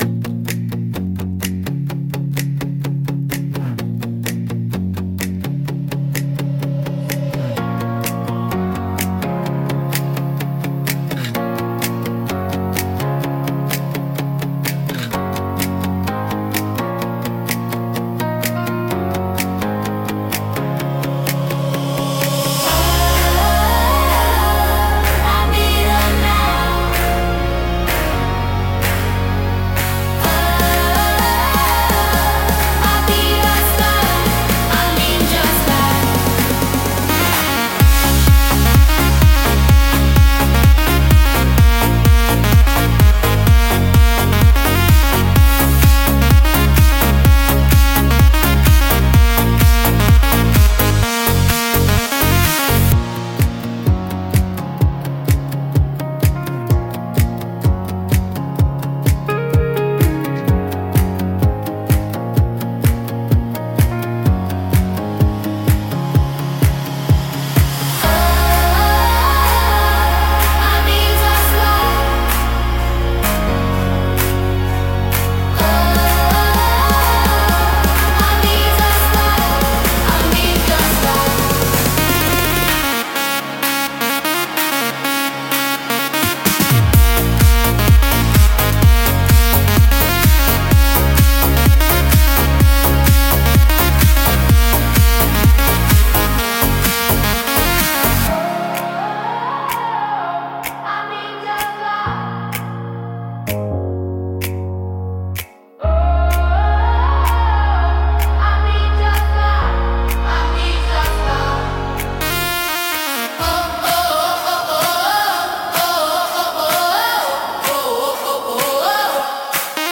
聴く人の気分を高め、集中力とパワーを引き出すダイナミックなジャンルです。